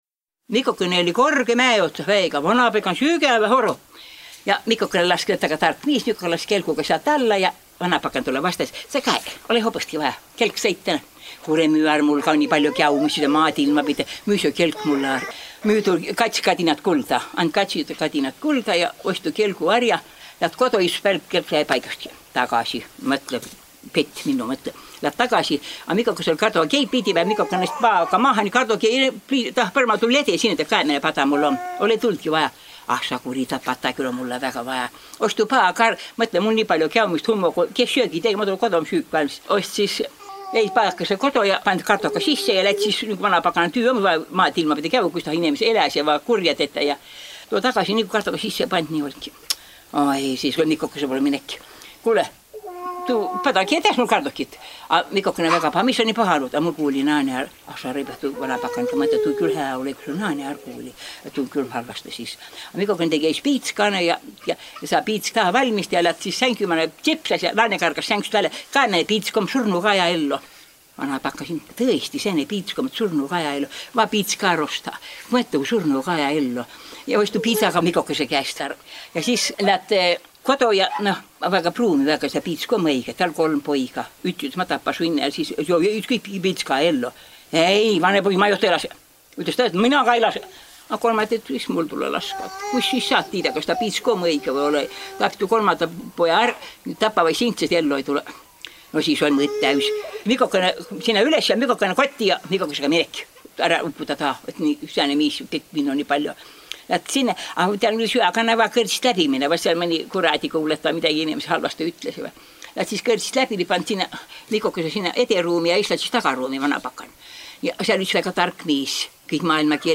Jutus “Vanapakan ja Mikokõnõ”
Saa pruuki ütehkuuh “Seto aabidsaga” (2011) J-tähe opmisõl vai eräle. Peri plaadi päält “Juttõ ja laulõ seto aabitsa mano”.